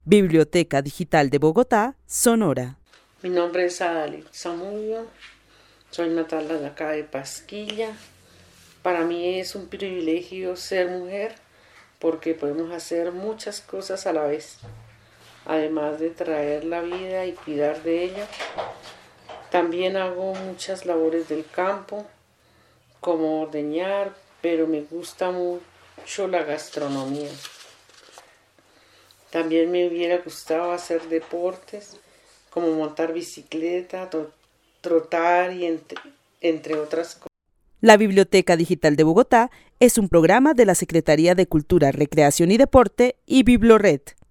Narrativas sonoras de mujeres